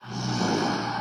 HippoSnores-006.wav